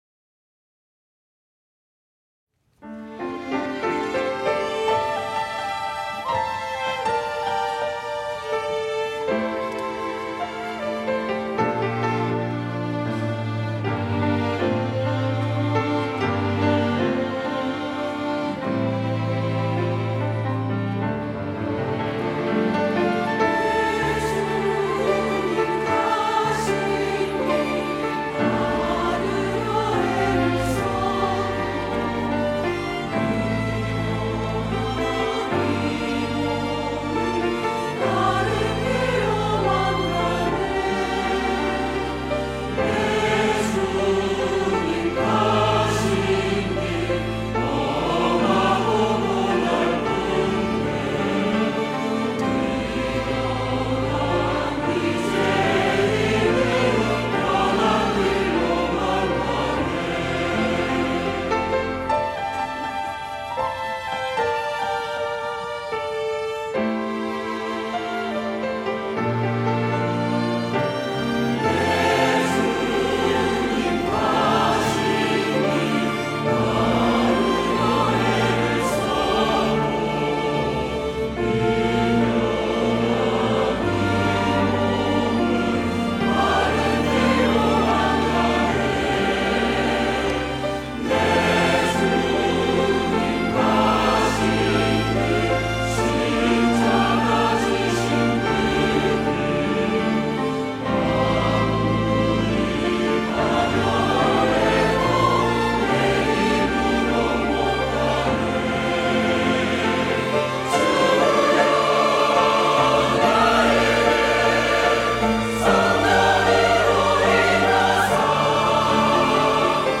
호산나(주일3부) - 내 주님 가신 길
찬양대